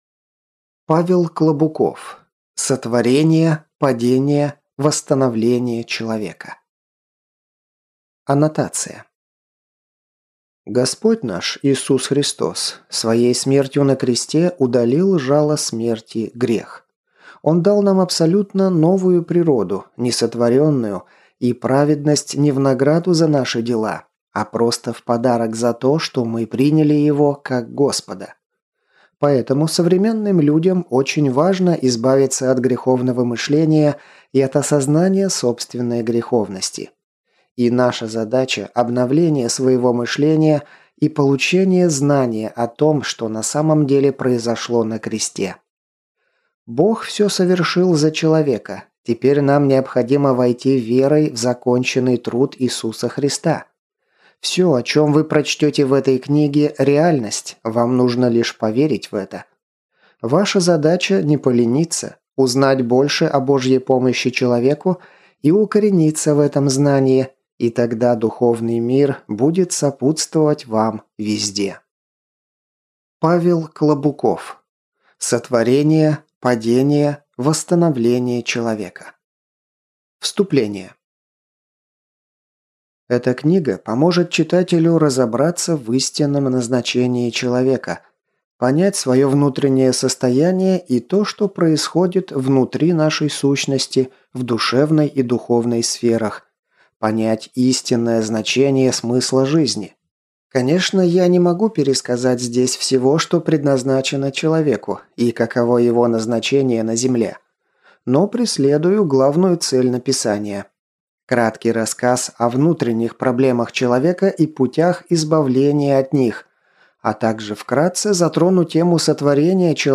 Аудиокнига Сотворение. Падение. Восстановление человека | Библиотека аудиокниг